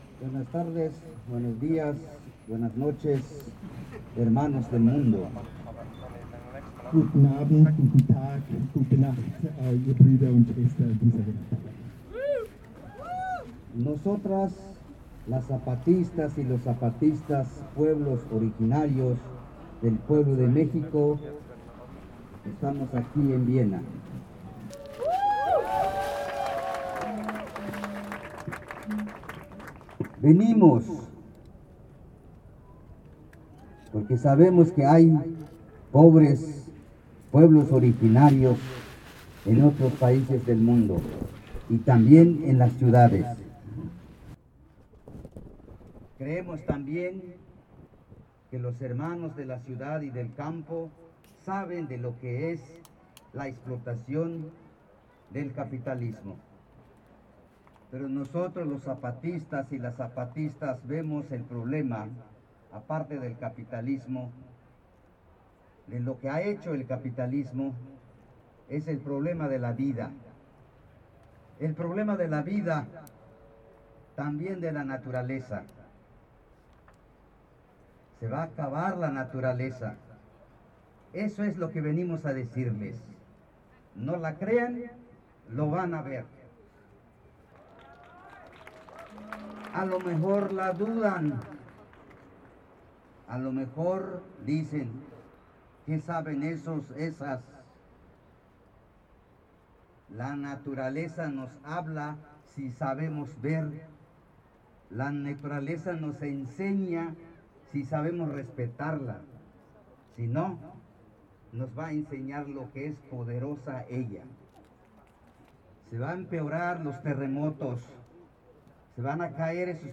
En el exterior del aeropuerto de Viena, la delegación zapatista fue recibida con mucha emoción y cálidas palabras por parte de mujeres austriacas y migrantas que les dieron la «bienvenida a su casa» en alemán y español. Sin quitarse la mochila, el Subcomandante Insurgente Moisés tomó el micrófono para dirigirse a sus anfitriones de Europa hablando de la defensa de la Madre Tierra.